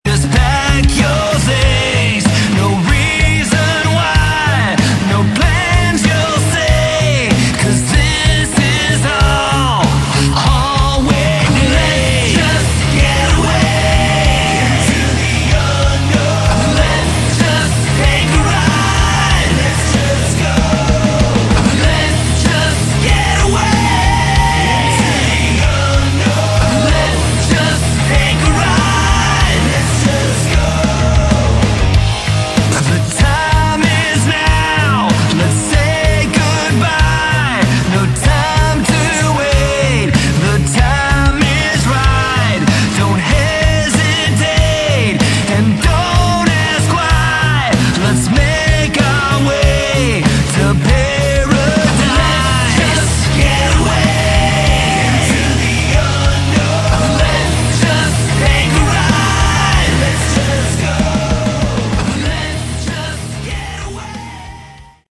Category: AOR / Melodic Rock
Guitars, Bass, Drums, Keys